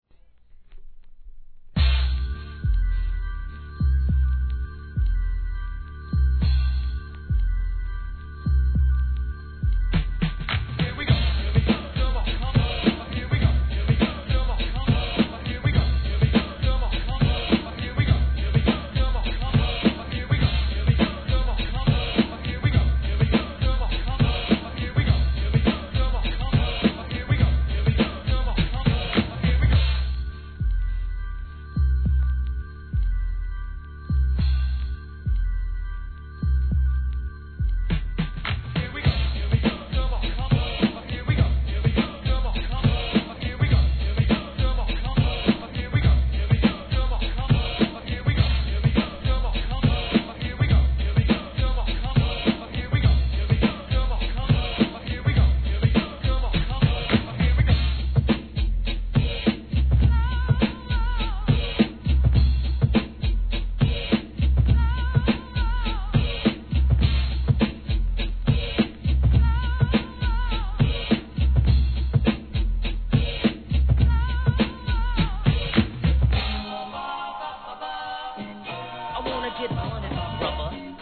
HIP HOP/R&B
かなりラガ色強の好作品！！